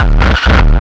HD BD 16  -L.wav